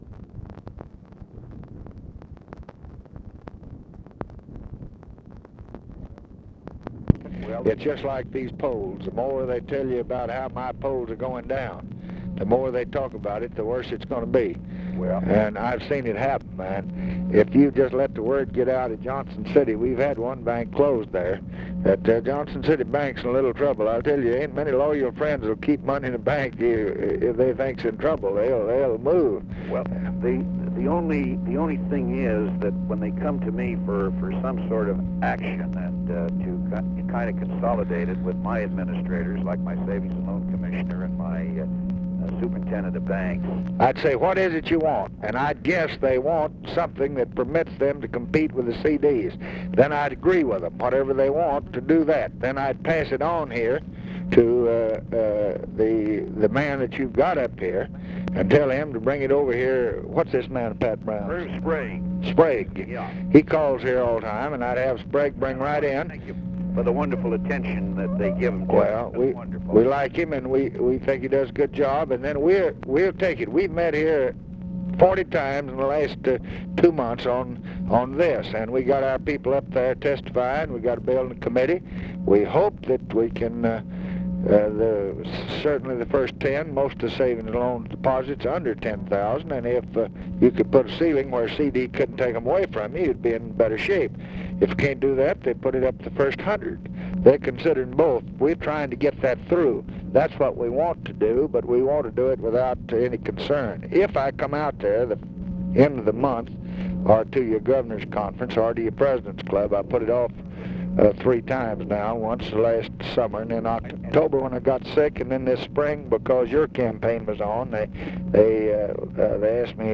Conversation with EDMUND G. BROWN, June 13, 1966
Secret White House Tapes